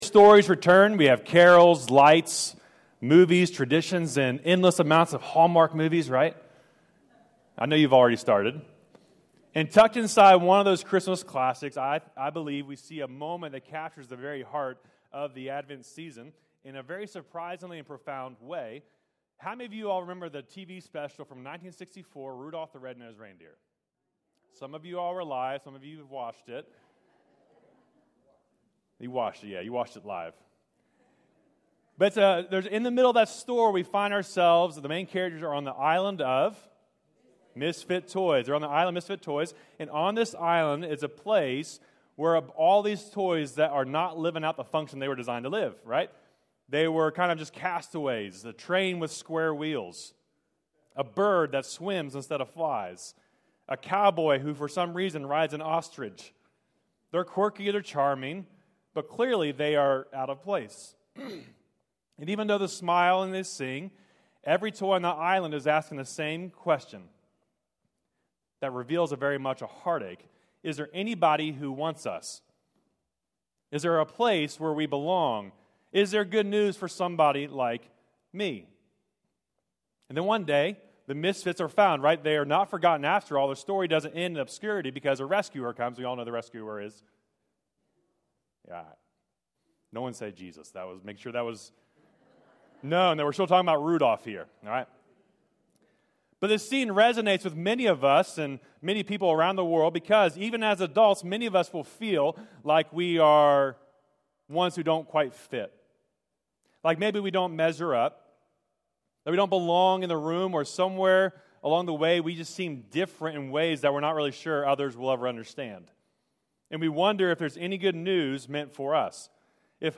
Stream or download recent messages from Clarksburg Baptist Church, today!